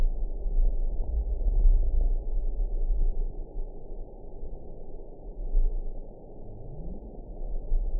event 922319 date 12/29/24 time 22:38:49 GMT (11 months ago) score 7.74 location TSS-AB10 detected by nrw target species NRW annotations +NRW Spectrogram: Frequency (kHz) vs. Time (s) audio not available .wav